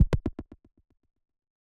wings.wav